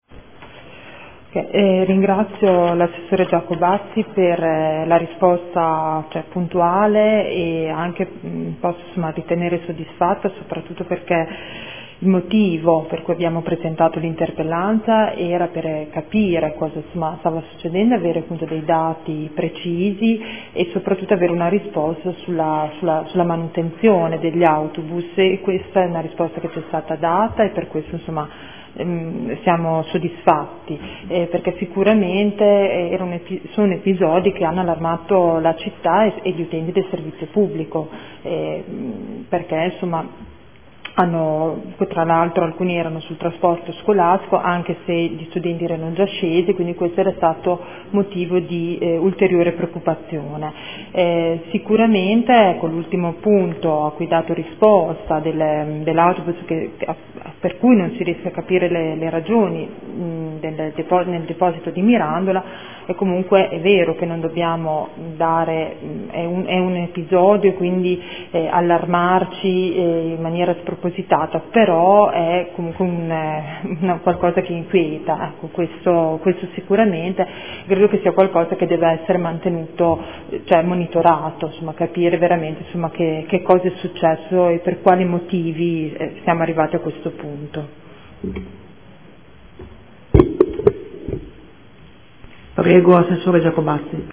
Seduta del 09/03/2015 Replica a risposta Assessore. Interrogazione della consigliera Baracchi (P.D.) avente per oggetto: “Incendi autobus Seta”